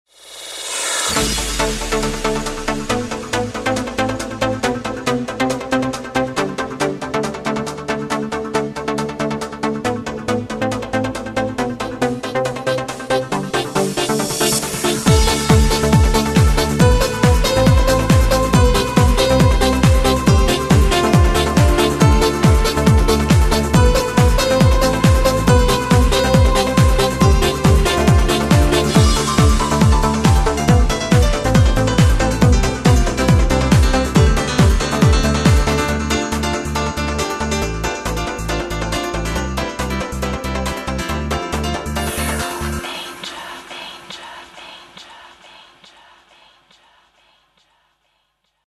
Категория: ЭЛЕКТРОННАЯ